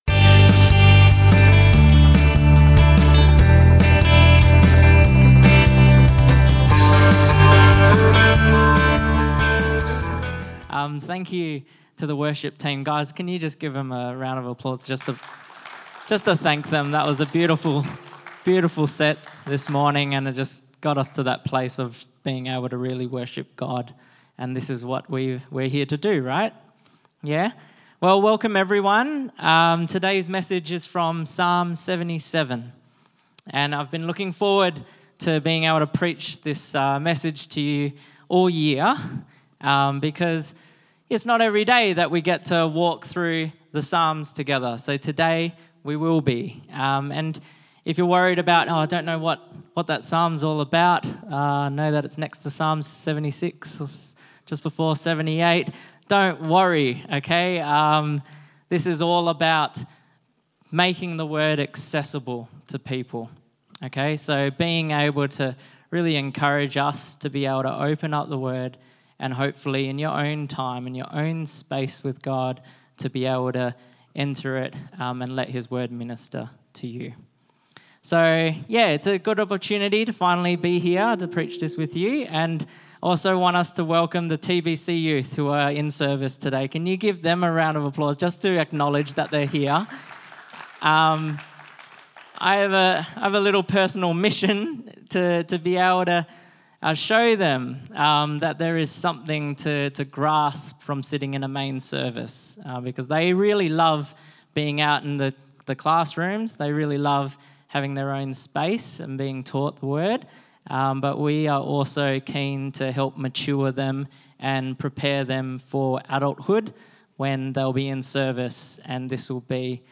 Sermons | Firstlight Church